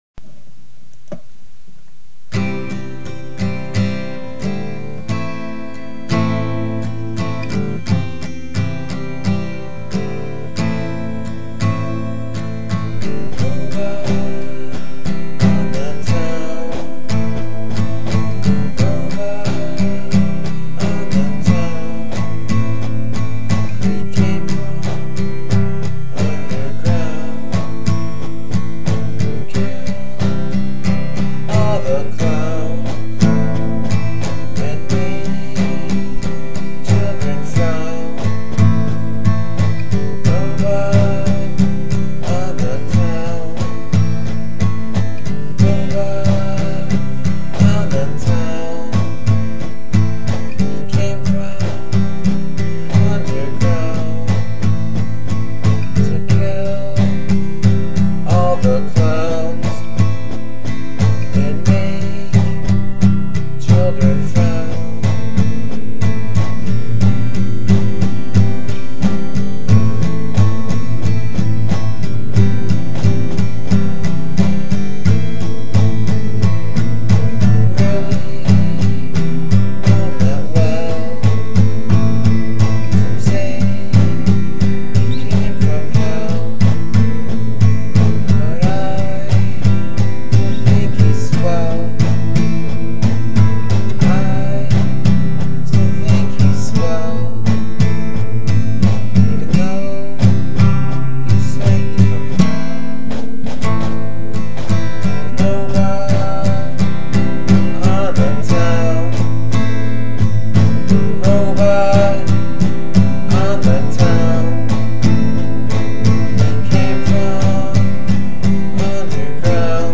a soothing song about a robot